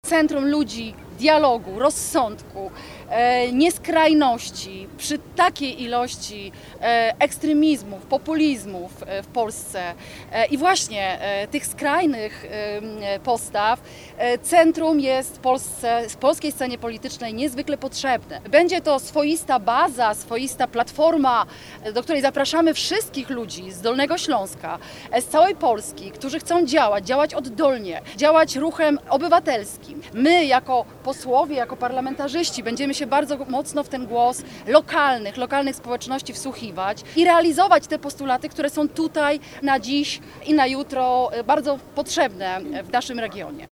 -Zakładamy nowy projekt Centrum na Dolnym Śląsku, bardzo potrzebny Polsce, mówi posłanka na Sejm Izabela Bodnar.